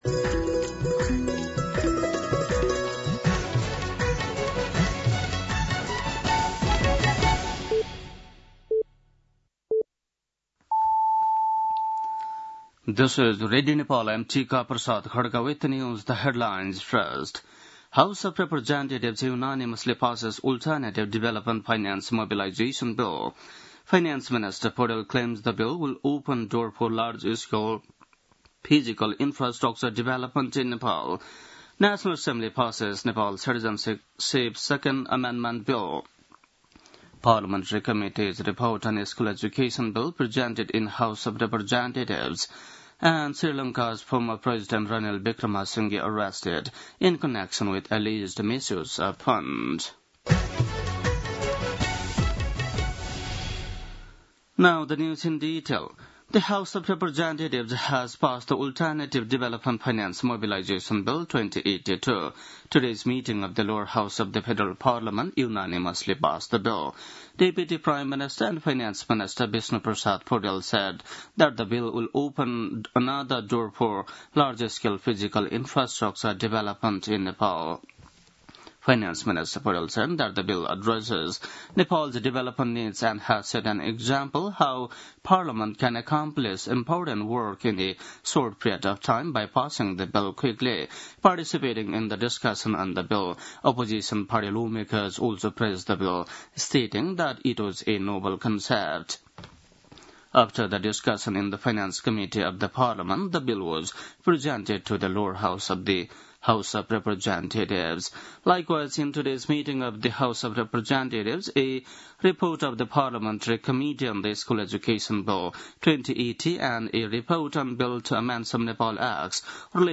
बेलुकी ८ बजेको अङ्ग्रेजी समाचार : ६ भदौ , २०८२